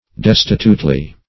destitutely - definition of destitutely - synonyms, pronunciation, spelling from Free Dictionary Search Result for " destitutely" : The Collaborative International Dictionary of English v.0.48: Destitutely \Des"ti*tute*ly\, adv.